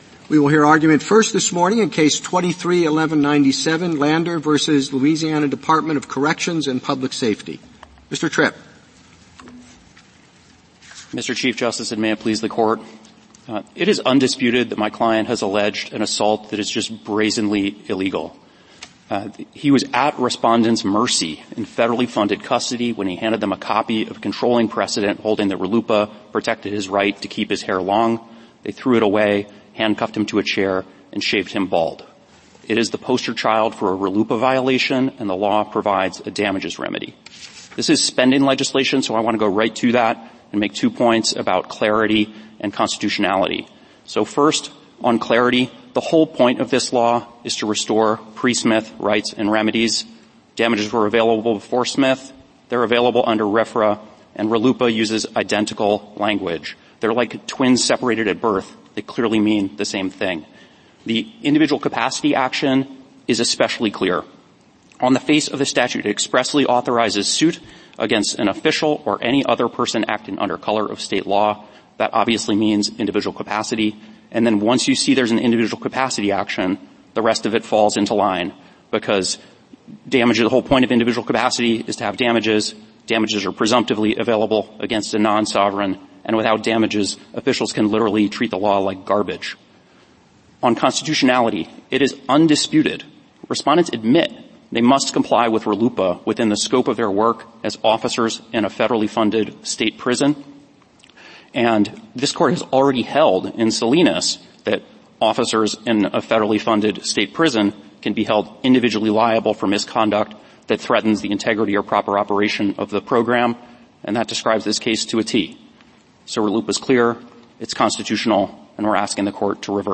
Supreme Court Oral Arguments